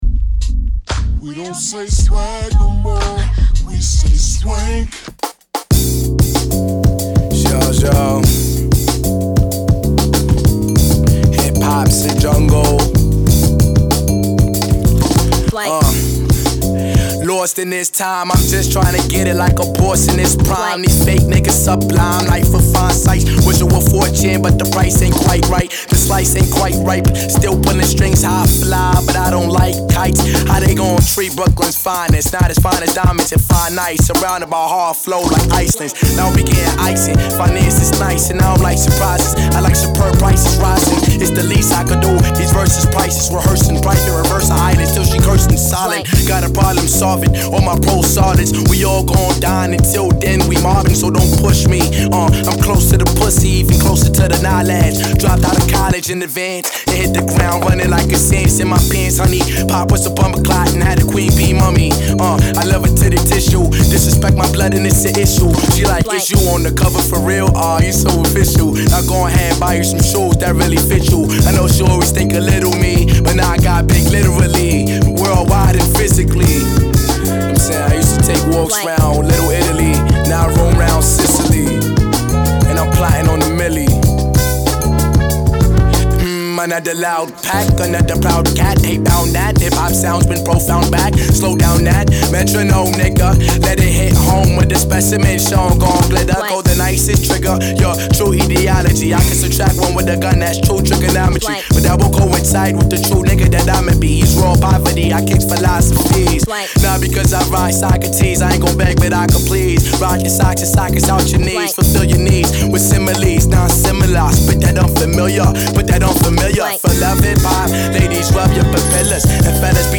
while gliding over soul drum fills and horn choruses.